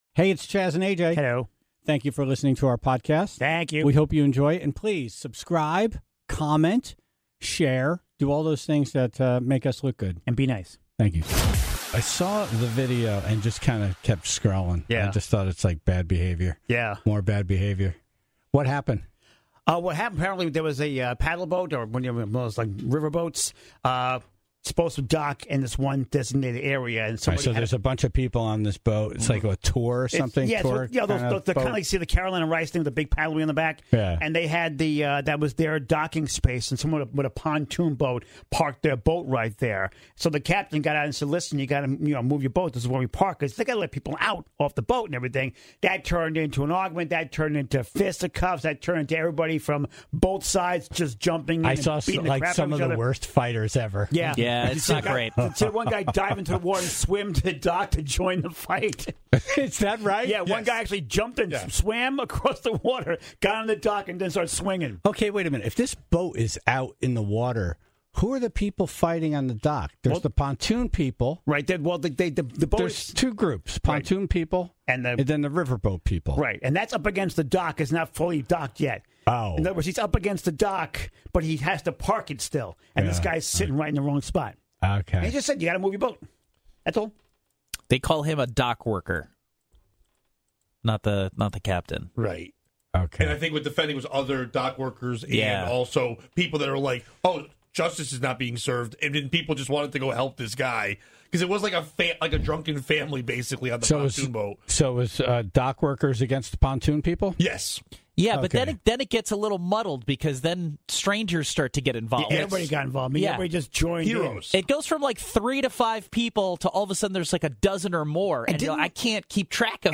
The Tribe called in their stories of having public fights, including on the softball field, and a bunch of drunken sailors in Gitmo.
The category was songs from the 1990's, and the Tribe was asked to hum the tune on the phone.